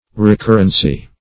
Meaning of recurrency. recurrency synonyms, pronunciation, spelling and more from Free Dictionary.
Search Result for " recurrency" : The Collaborative International Dictionary of English v.0.48: Recurrence \Re*cur"rence\ (r?*k?r"rens), Recurrency \Re*cur"ren*cy\ (-ren*s?), n. [Cf. F. r['e]currence.]